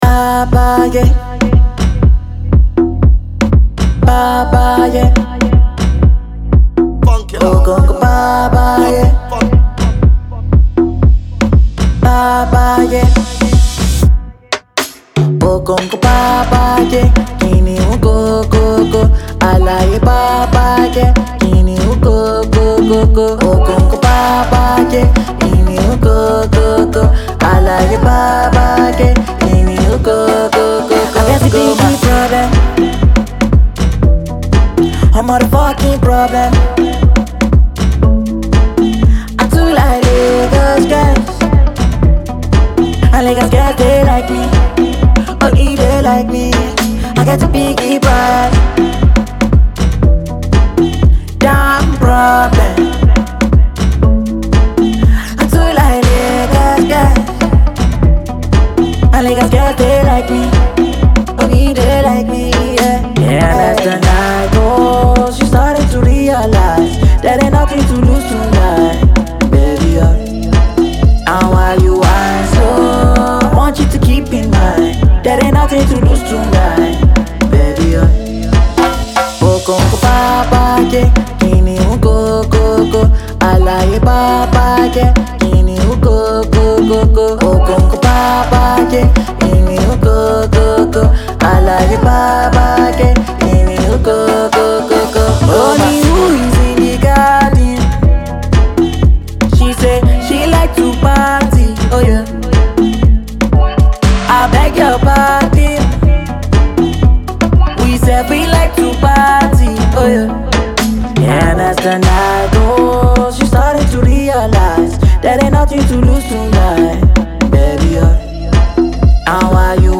is a fun sound with an Afro-house bounce